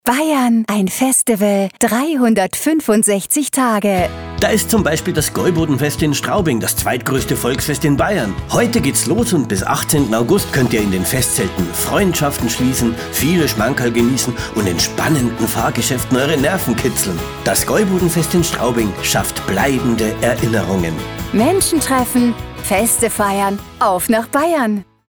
• Produktion eines 25-Sekunden-Audio-Spots mit professioneller Sprecherin – kombiniert aus einem starken Kampagnen-Intro und 20 Sekunden für eure individuellen Inhalte (eine Veranstaltung pro Buchung)